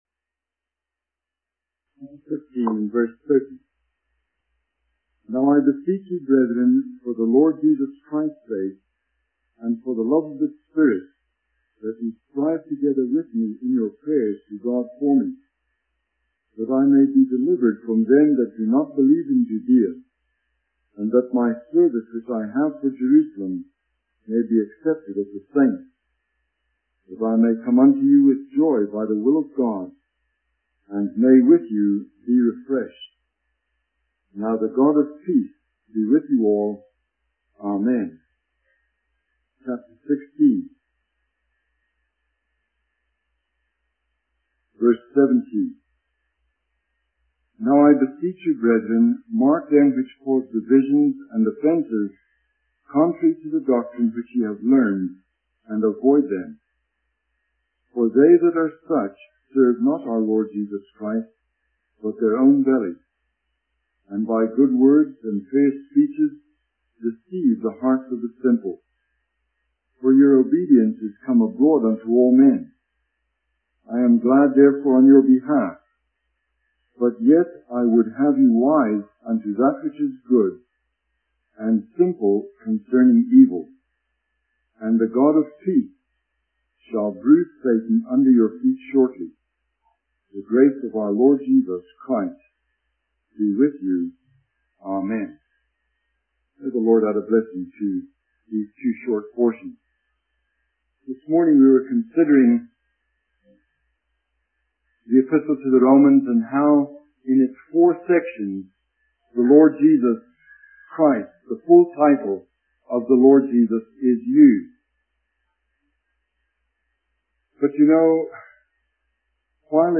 The video is a sermon transcript that covers various topics. It starts with a description of a powerful waterfall and the awe it inspires, relating it to the anticipation of seeing and hearing Jesus.